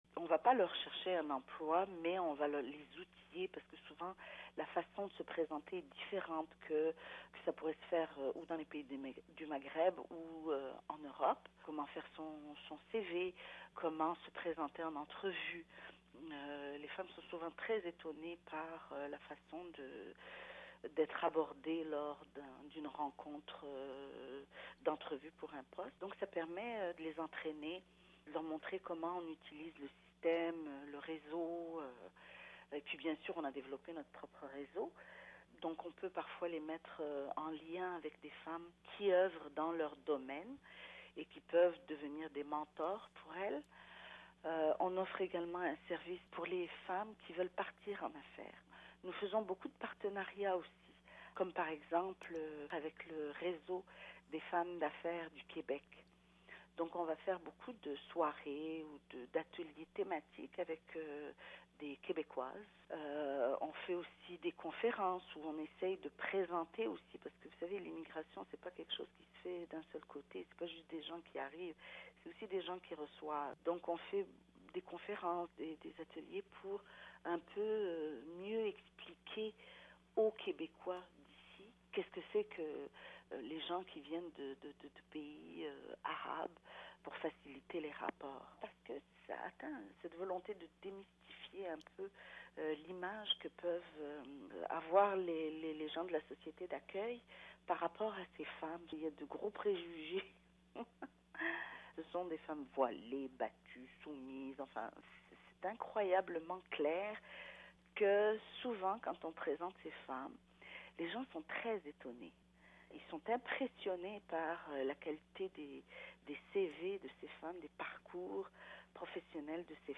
Voici une entrevue